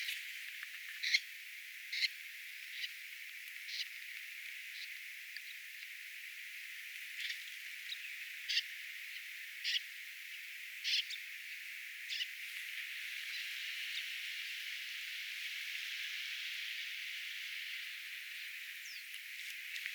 tuollainen kurpan ääntely
Taustalla pulmussirrinuori, pajusirkku.
tuollainen_kurpan_aantely_pulmussirrinuori_pajusirkku.mp3